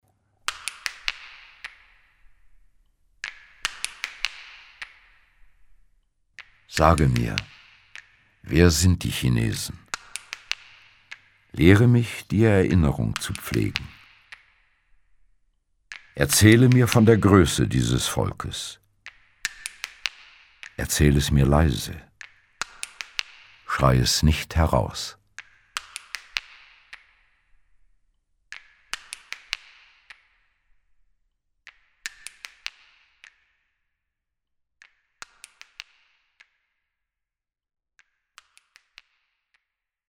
Trotz der Kompaktheit dieser Kulturgeschichte wirkt das weder anstrengend noch angestrengt, wozu auch der sachlich mit sonorer Stimme vortragende Rolf Becker entscheidend beiträgt. Hier wird kein magerer Abriss abgeliefert sondern ein kunstreiches Gewebe aus präzise ausgewählter Information, geschickt eingestreuten literarischen Perlen und pointierender Musik.